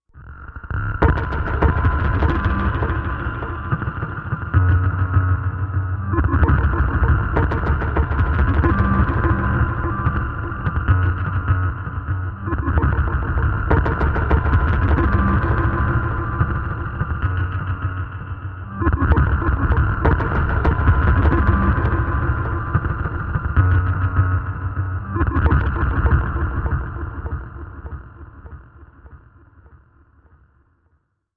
Rectifier, Machine, Space Pulses, Computer Flux, Echoes, Hum